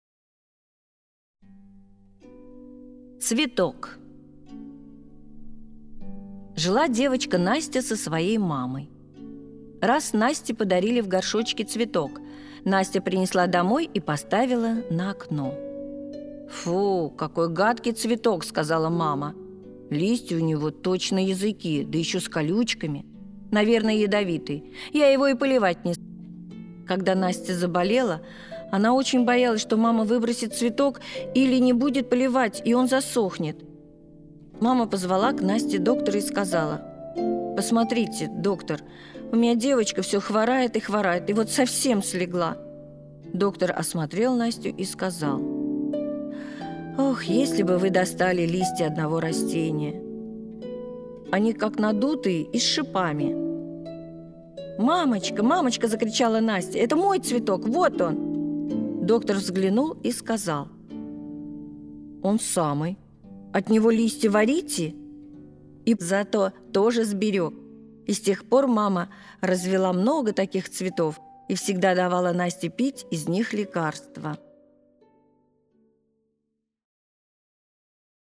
Аудиорассказ «Цветок»